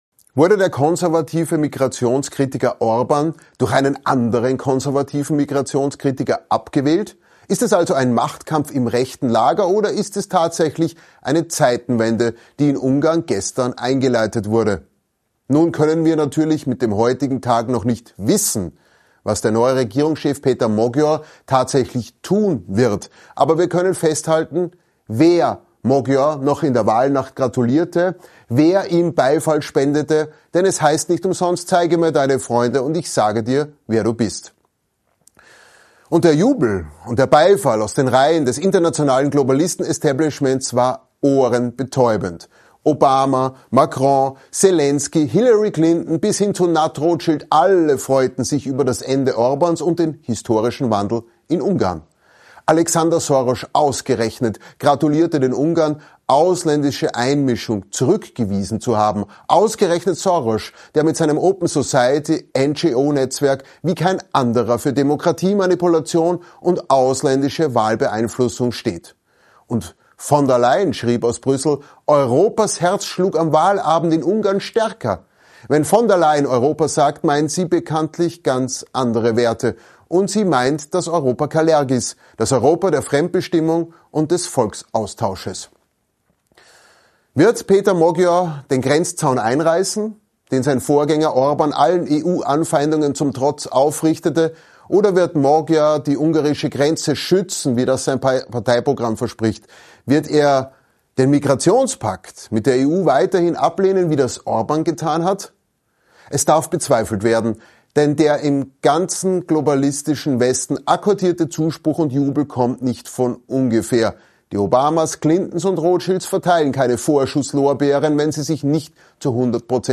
Kommentar: Wird Magyar ein „junger Orban“ – oder ein Vasall der Globalisten?